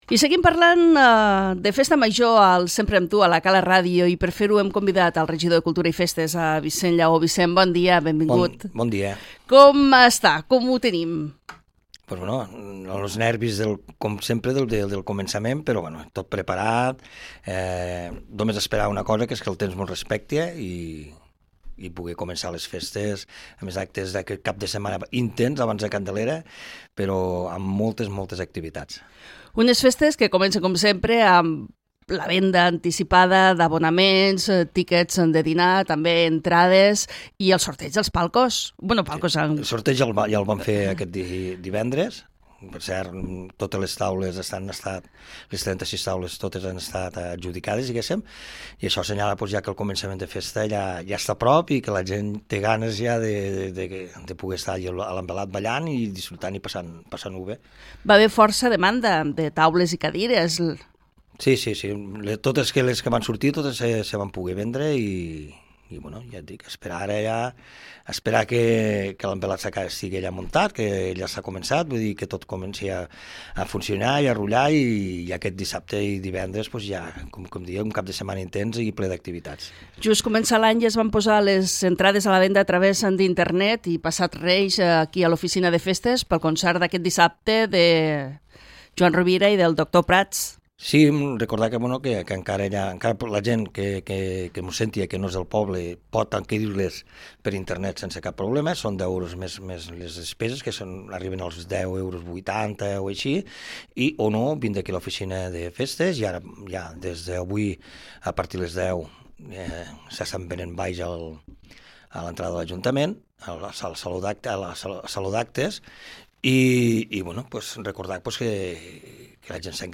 Vicenç Llaó, regidor de Festes